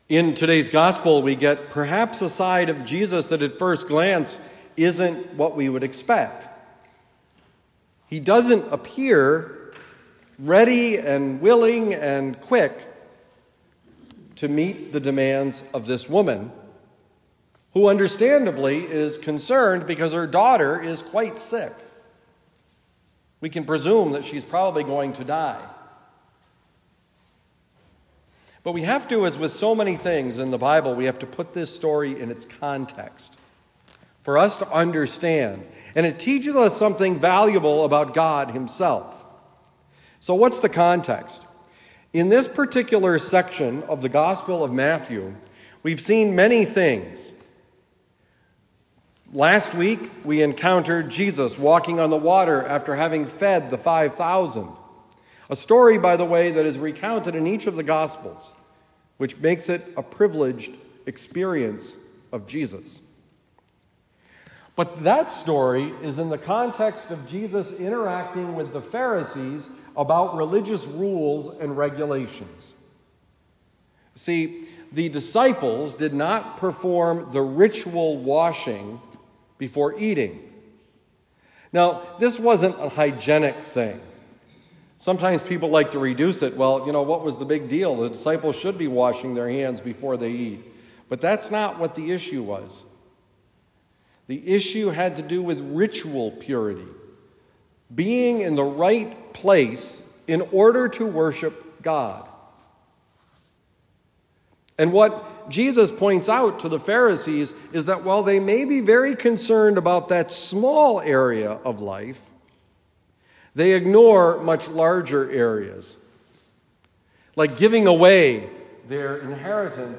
Homily given at Holy Angels Parish, Wood River, Illinois.